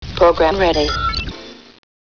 (Computervoice: program ready...)